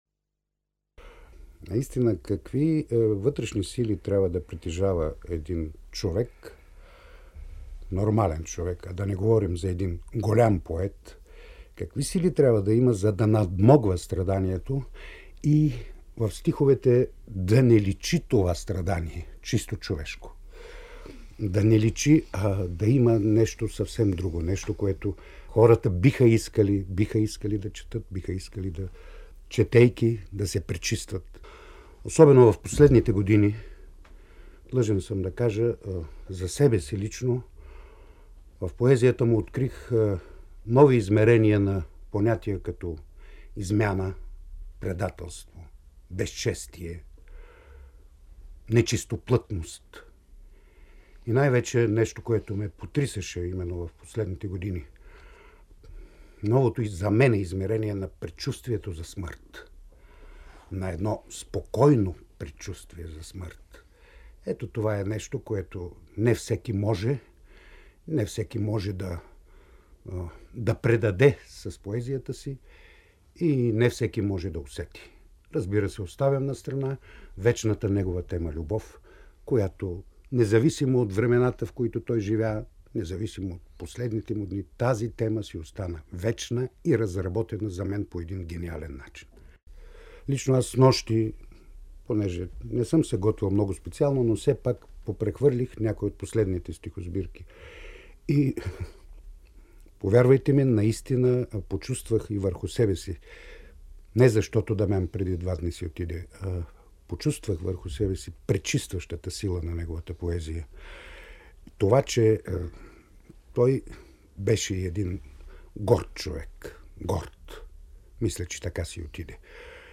За поезията на Дамян Дамянов говори и един от неговите най-известни рецитатори, актьорът Сава Хашъмов: „Лаская се от мисълта, че съм един от тези, които през годините са чули и разбрали Дамян Дамянов, така както трябва“, казво той в интервю за БНР през 1999 година.